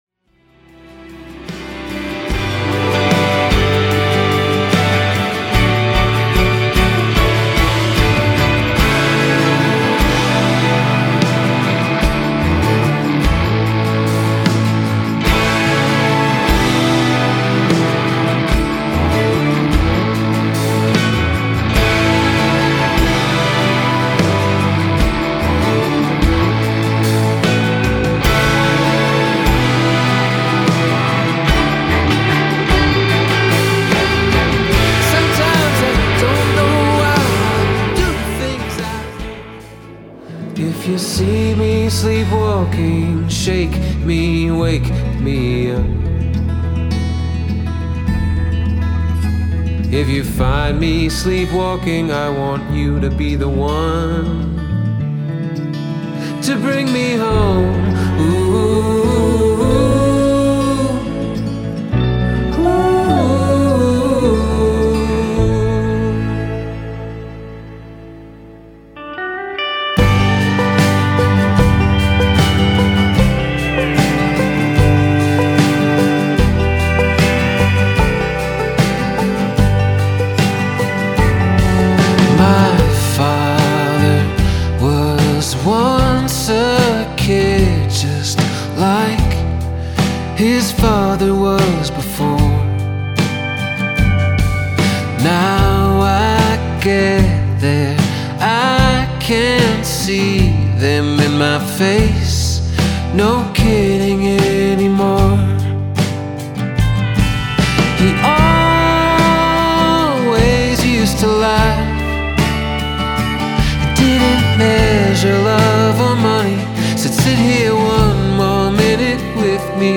Drums and percussion